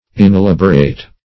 Inelaborate \In`e*lab"o*rate\, a. [L. inelaboratus.